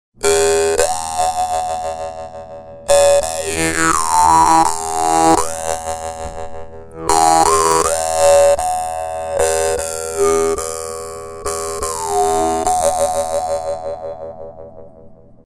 Maultrommel Dan Moi Bass
Die große Schwester der Standard Dan Moi Standard ist etwas größer und hat einen tieferen Klang.
Die vietnamesischen Maultrommeln zeichnen sich durch ihre einfache Spielbarkeit, ihren schönen, obertonreichen Klang und den günstigen Preis aus.
Dabei erinnert ihr Sound an elektronische Klänge analoger Synthesizer – ganz ohne Technik.